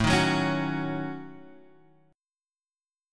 A6b5 chord